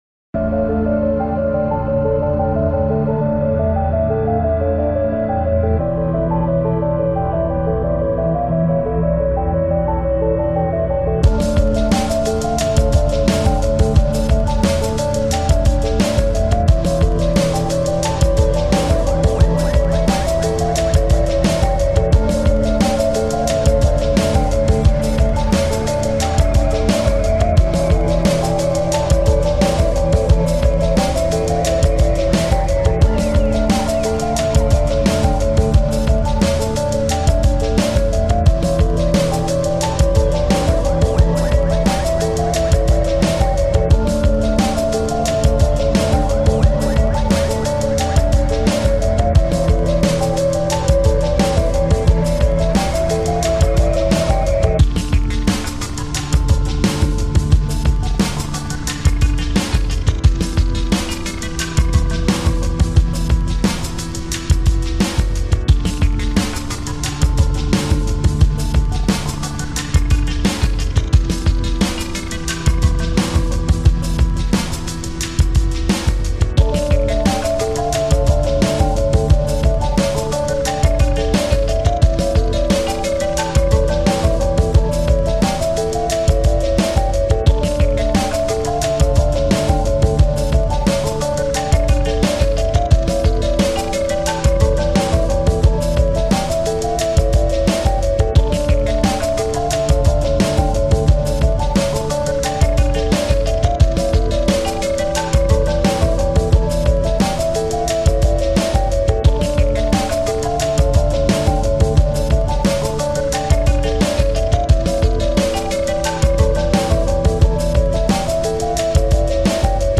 захватывающий трек в жанре синт-поп